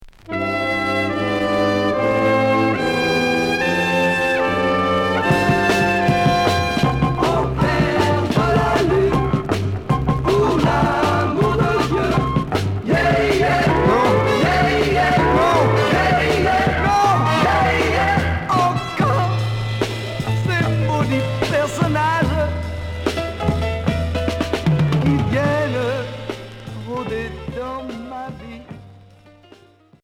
Pop rock